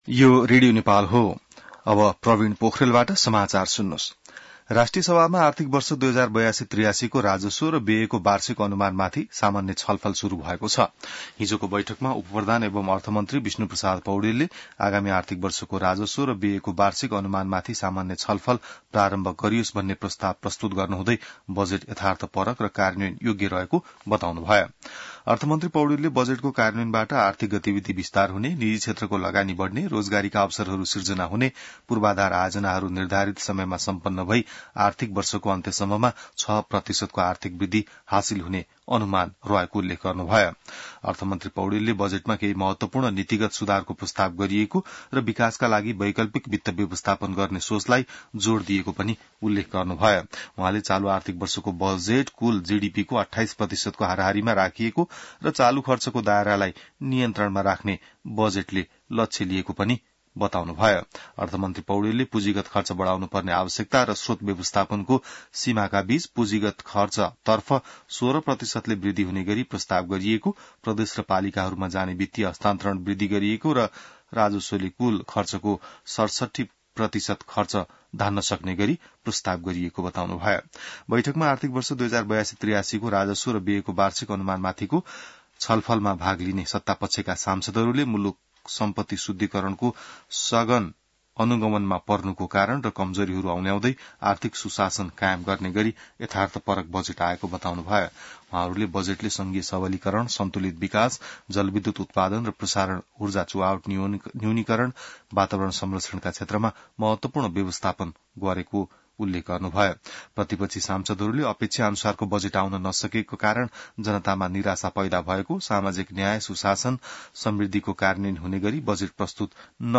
बिहान ६ बजेको नेपाली समाचार : २२ जेठ , २०८२